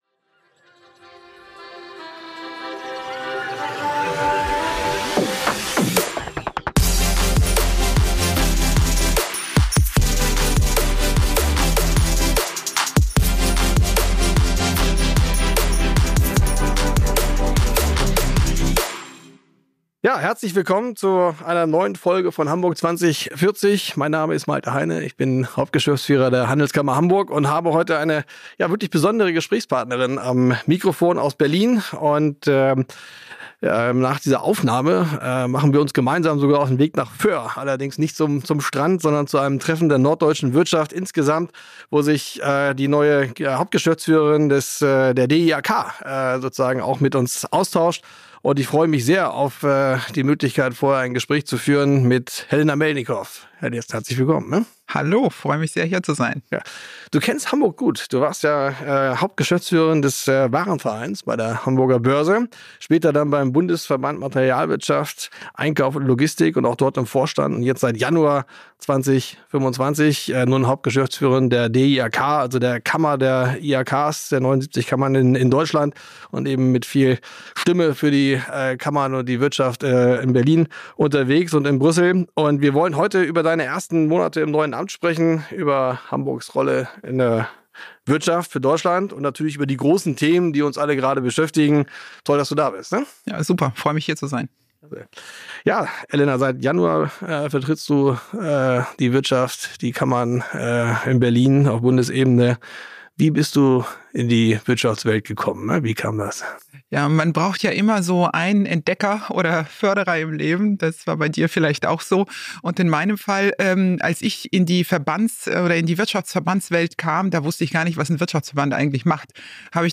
Ein ehrlicher Talk über wirtschaftspolitische Realität und die Kraft eines starken Netzwerks – mit Blick auf Deutschland im Jahr 2040.